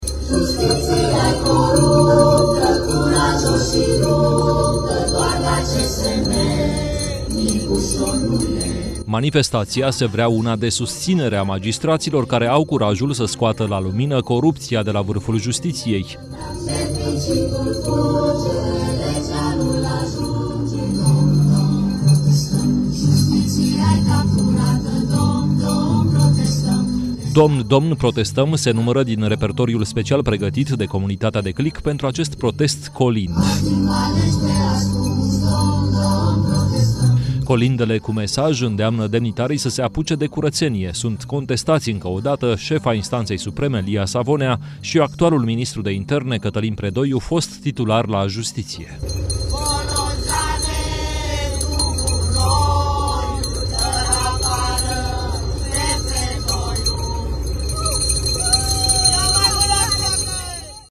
Protest „colind” la Palatul Cotroceni, în timpul discuției inedite avute de șeful statului cu actuali și foști magistrați, organizat de comunitatea „Declic”. Între colindele adaptate se numără: „Domn, domn, protestăm” și „Magistrații se-ntâlniră”.
„Domn, domn, protestăm” se numără din repertoriul special pregătit pentru acest protest „colind”.